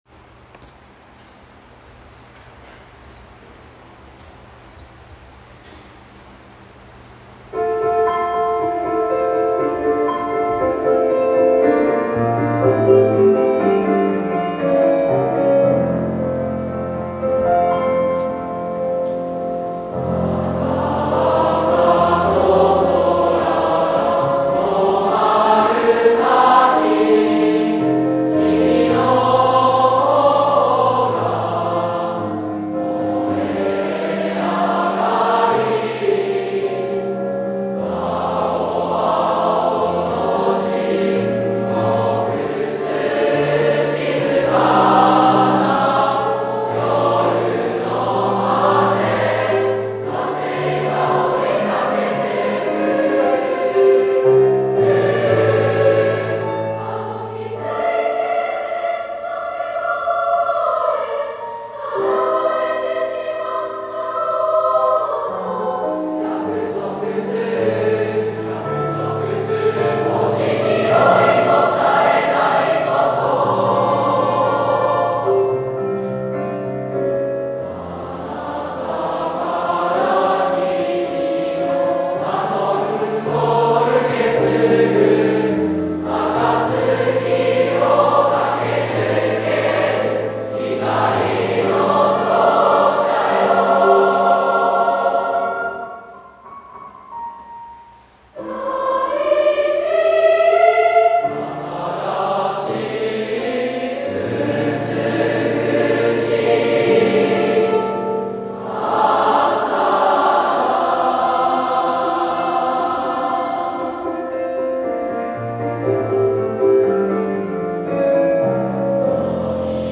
令和６年度の全校追求曲は、信長高富／混声合唱曲「光の走者よ」（作詞：和合亮一）です。
第８回全校音楽集会（６月19日）の合唱音源（m4aファイル）